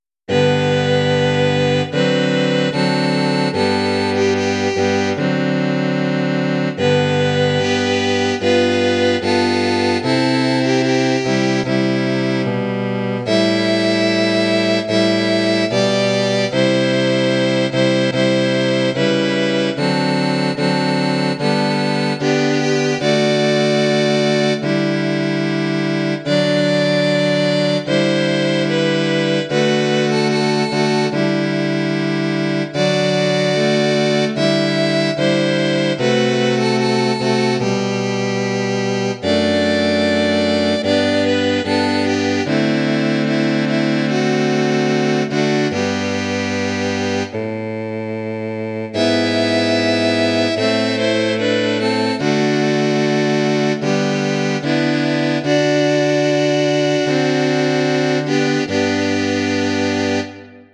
Bearbeitung für Streichquartett
Besetzung: Violine 1, Violine 2, Viola, Violoncello
Arrangement for string quartet
Instrumentation: violin 1, violin 2, viola, violoncello